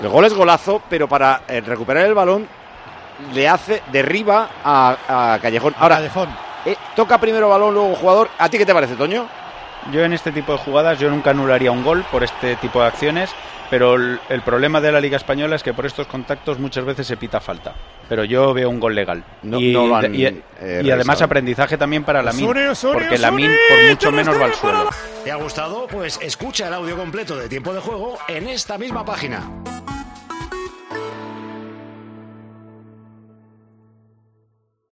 Durante la retransmisión del choque en Tiempo de Juego, el excolegiado internacional y actual especialista arbitral del programa líder de la radio deportiva del fin de semana, Mateu Lahoz, dio su opinión sobre esa jugada.